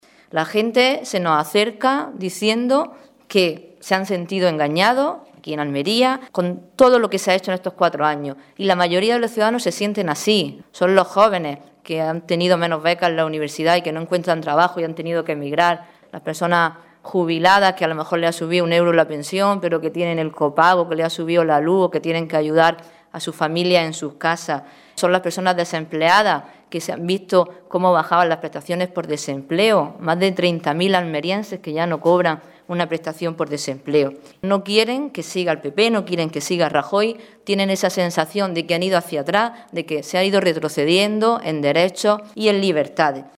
Rueda de prensa que han ofrecido los cabezas de lista del PSOE de Almería al Congreso y el Senado, Sonia Ferrer y Juan Carlos Pérez Navas, para informar sobre la campaña electoral